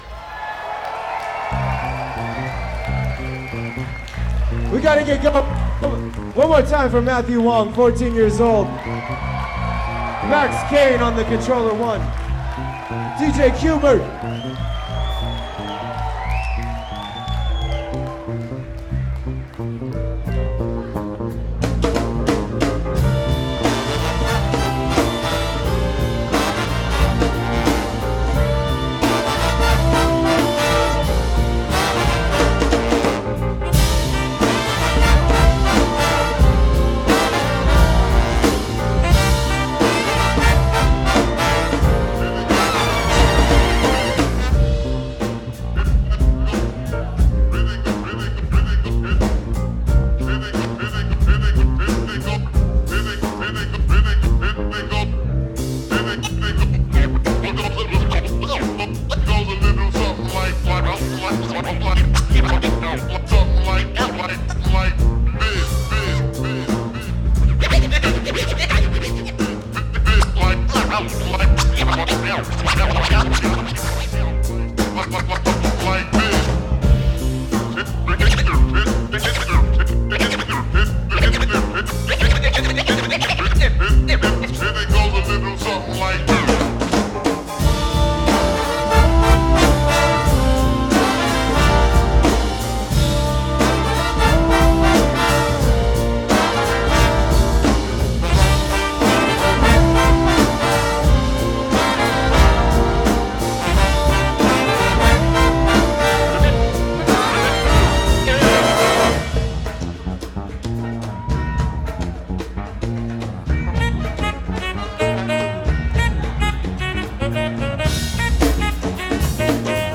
Encore / Last Song of the Night